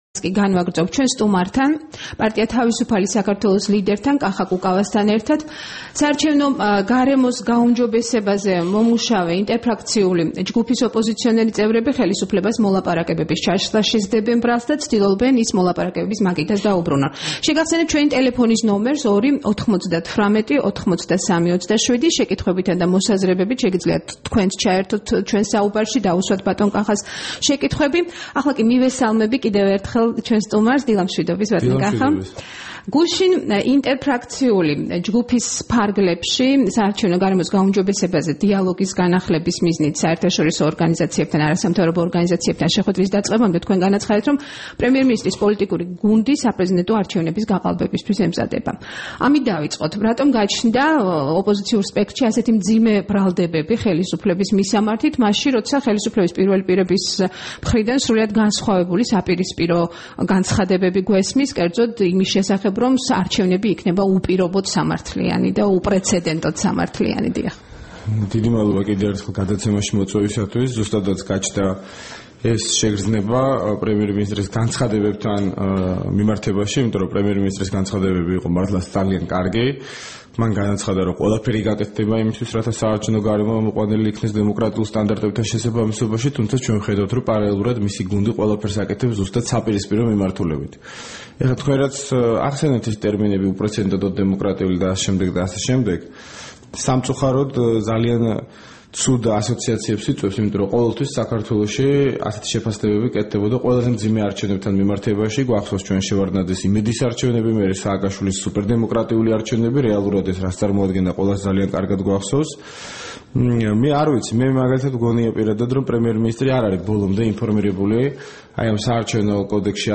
23 ივლისს რადიო თავისუფლების დილის გადაცემის სტუმარი იყო კახა კუკავა, ”თავისუფალი საქართველოს” ლიდერი.
საუბარი კახა კუკავასთან